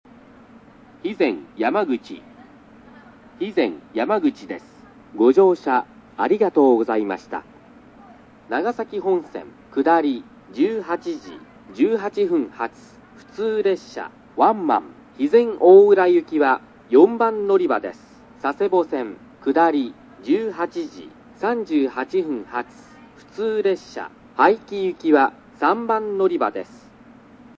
また、男性と女性声のある駅では、奇数番線が「女性」偶数番線が「男性」ですが、肥前山口では１・２番線が女性、３・４番線は男性と異なります。
男性の「到着放送」のイントネーションが不自然なような…　機械チックな声です。
スピーカー：UNI-PEX・SC-10JA（ソノコラム）
音質：A
３番のりば 到着放送　(134KB/27秒)